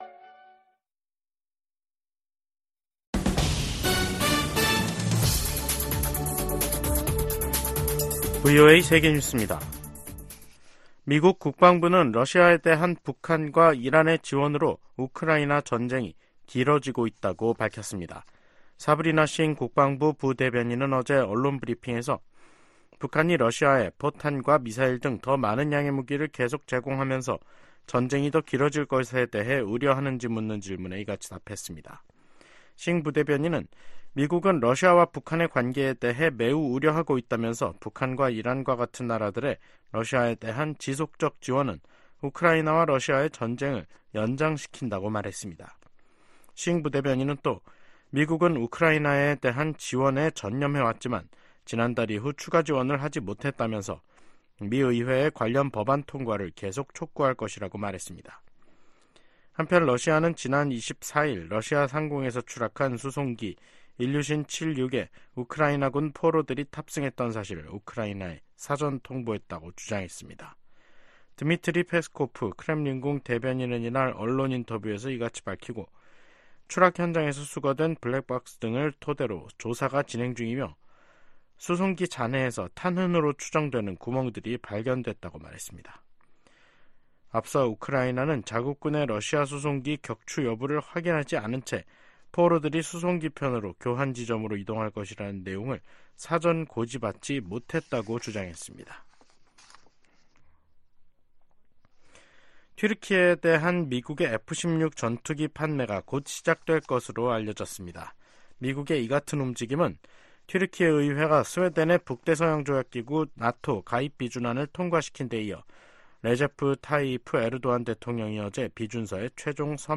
VOA 한국어 간판 뉴스 프로그램 '뉴스 투데이', 2024년 1월 26일 2부 방송입니다. 제네바 군축회의에서 미국과 한국 등이 북한의 대러시아 무기 지원을 규탄했습니다. 미 국방부는 북한의 대러시아 무기 지원이 우크라이나 침략 전쟁을 장기화한다고 비판했습니다. 김정은 북한 국무위원장은 지방 민생이 생필품 조차 구하기 어려운 수준이라면서, 심각한 정치적 문제라고 간부들을 질타했습니다.